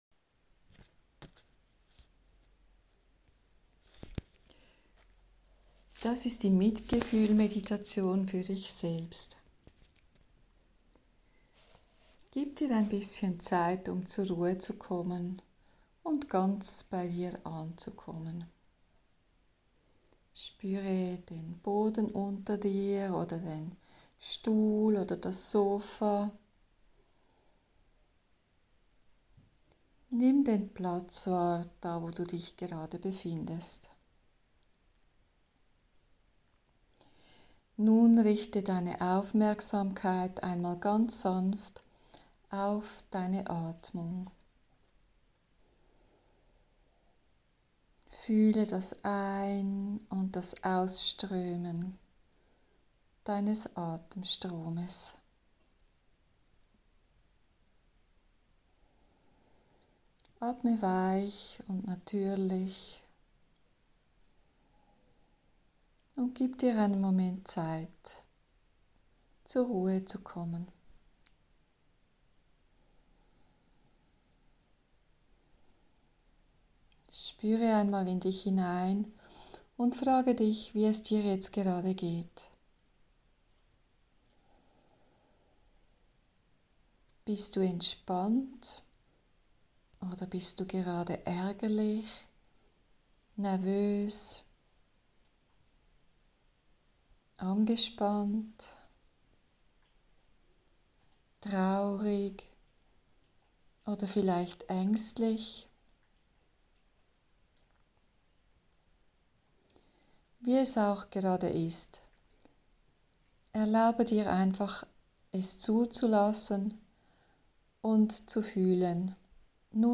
mitgefuehlsmeditation-fuer-sich-selber.mp3